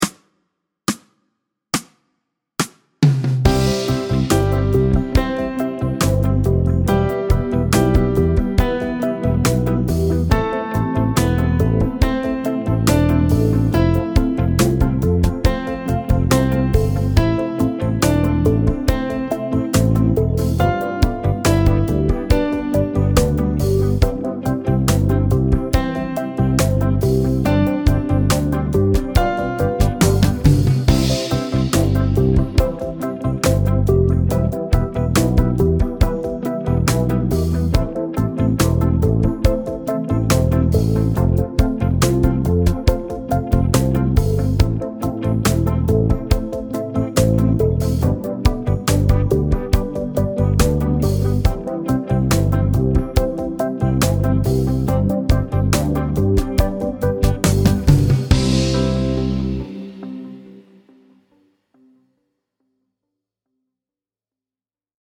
Medium C instr (demo)